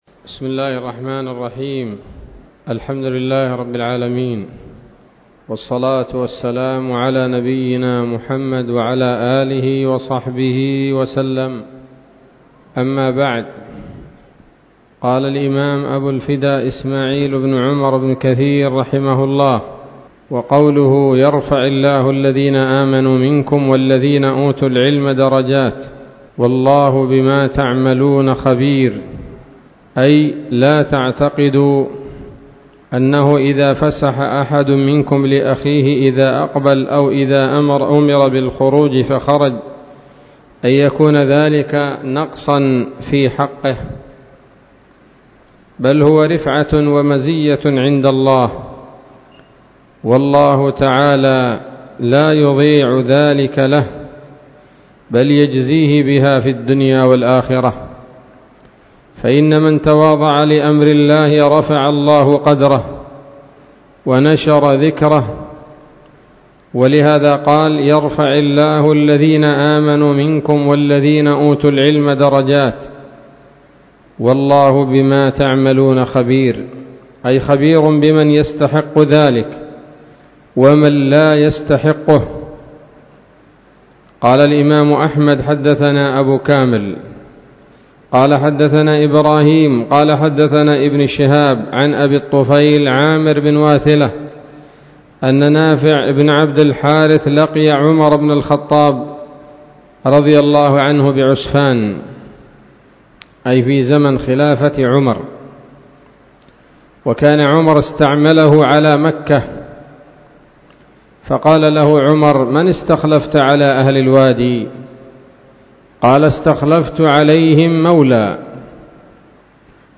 الدرس السابع من سورة المجادلة من تفسير ابن كثير رحمه الله تعالى